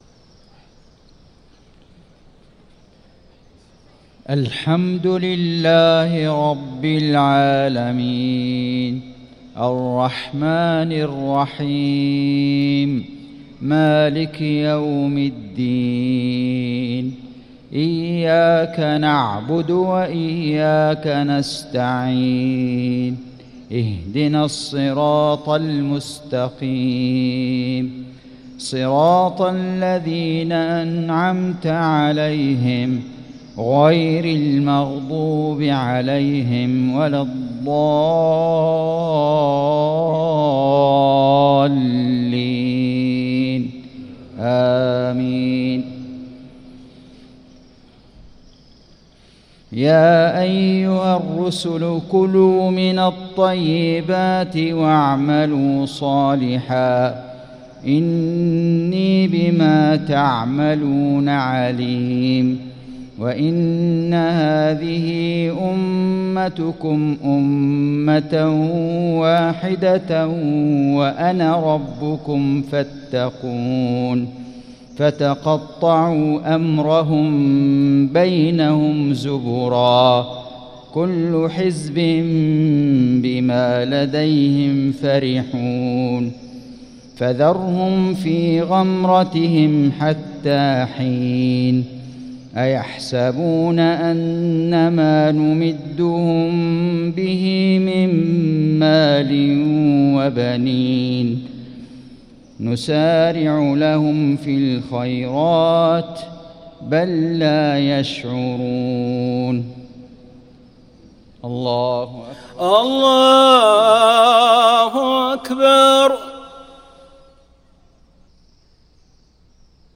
صلاة المغرب للقارئ فيصل غزاوي 2 رمضان 1445 هـ
تِلَاوَات الْحَرَمَيْن .